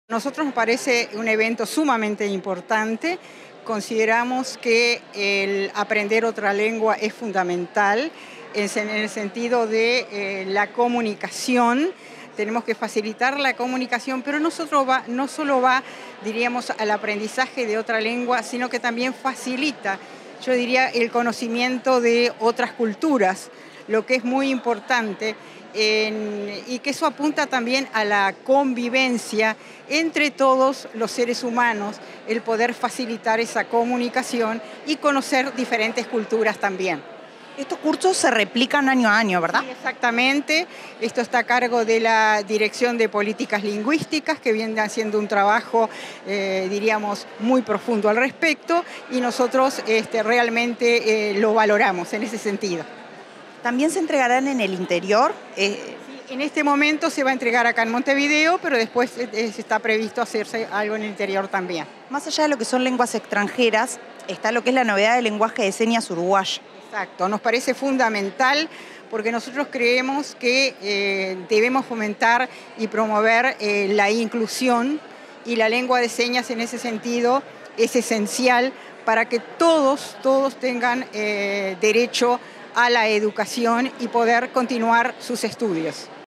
Entrevista a la consejera de ANEP, Dora Graziano
Luego de la entrega de diplomas a Egresados 2022 Montevideo, Alemán, Francés, Italiano, LSU y Portugués, este 23 de octubre, Comunicación Presidencial